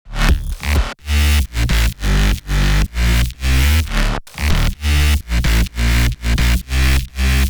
BASS HOUSE KITS
Ace_126 – Bass_Full_2 (Sidechained)
ACE_-2-Ace_126-Bass_Full_2-Sidechained.mp3